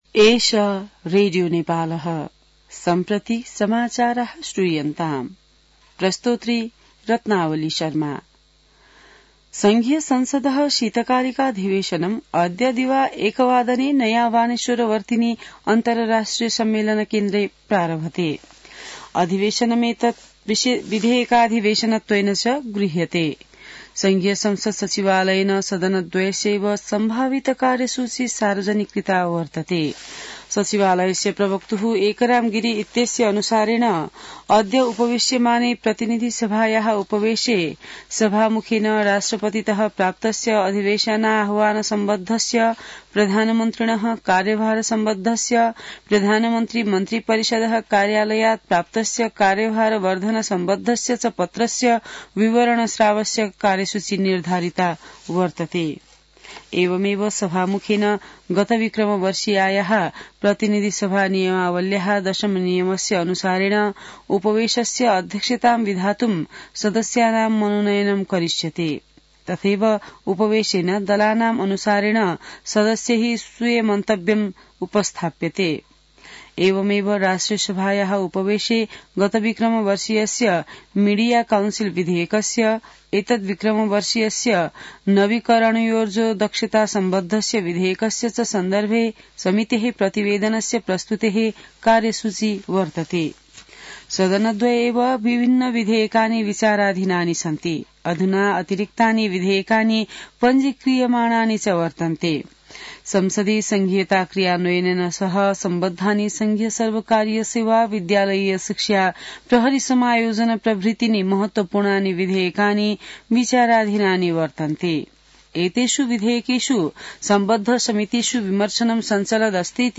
संस्कृत समाचार : १९ माघ , २०८१